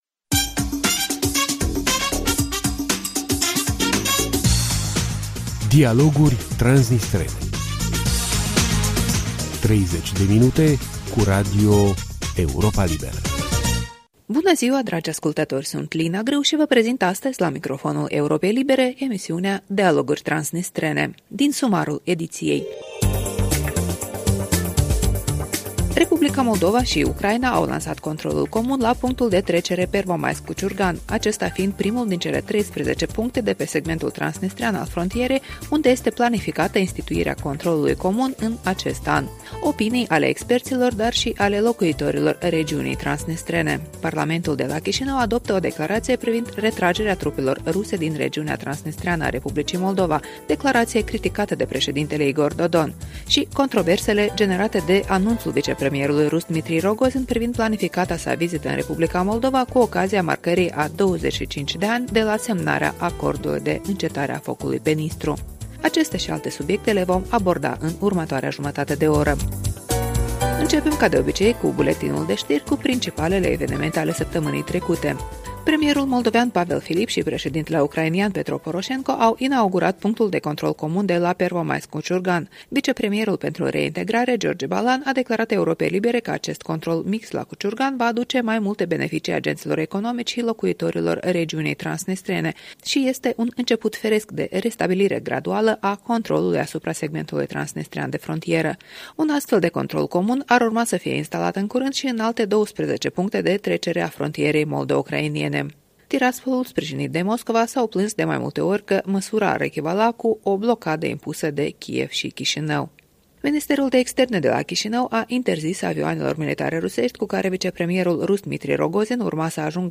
Bună ziua, dragi ascultători!